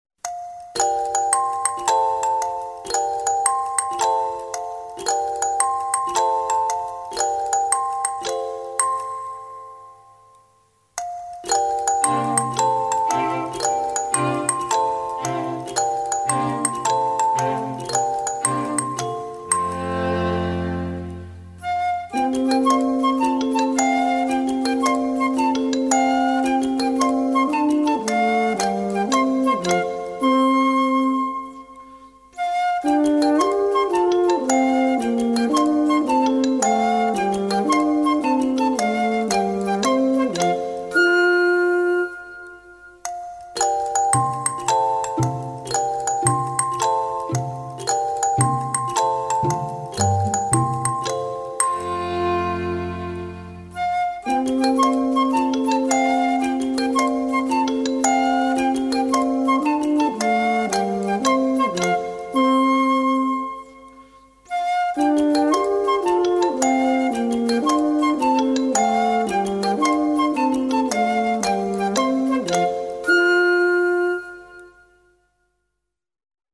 • Category: Classical music for children